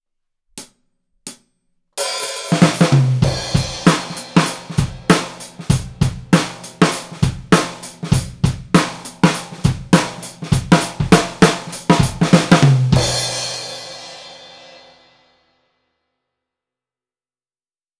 すべて、EQはしていません。
②　ドラム目の前
先ほどより、グッと低音が出てきてタイコ類の芯が録れてきましたね！